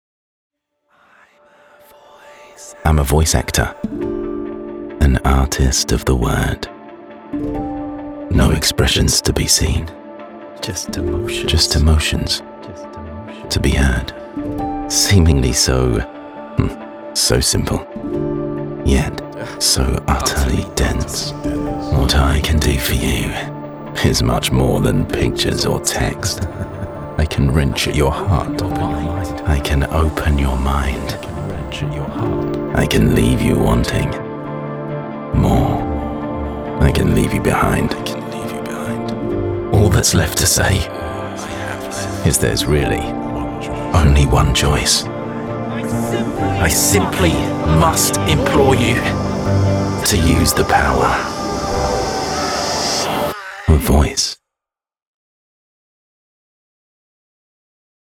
English (British)
I have a pro-grade studio with a dedicated soundproof booth.
BaritoneDeepLow